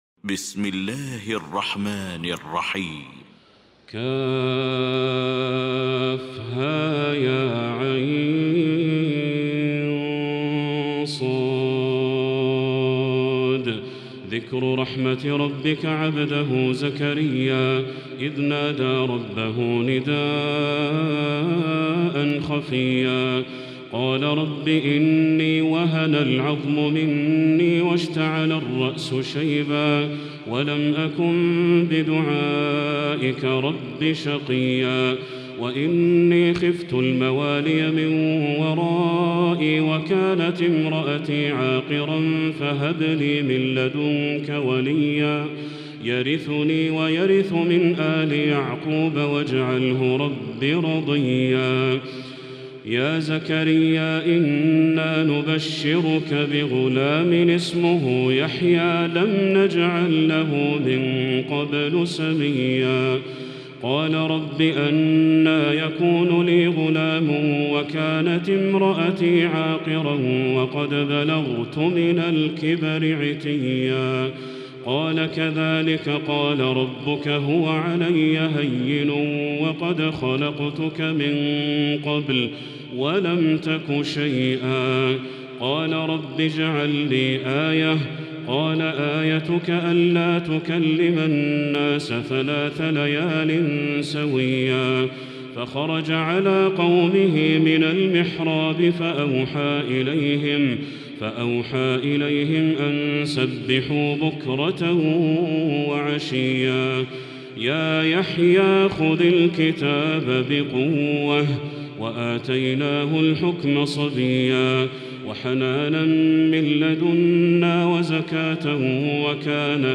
المكان: المسجد الحرام الشيخ: بدر التركي بدر التركي مريم